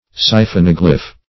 Search Result for " siphonoglyphe" : The Collaborative International Dictionary of English v.0.48: Siphonoglyphe \Si`pho*nog"ly*phe\, n. [Siphon + Gr.
siphonoglyphe.mp3